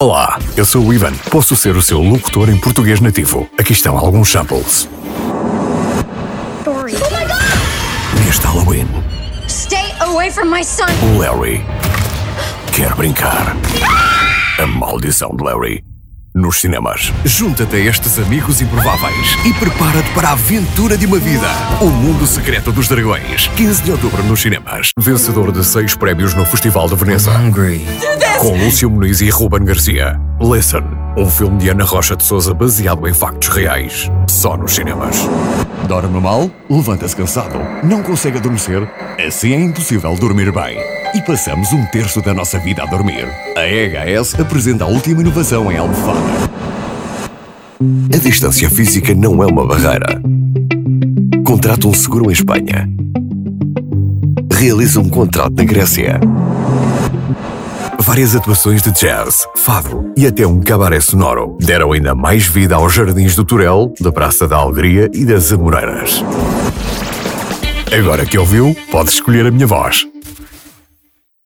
电影片花【角色扮演】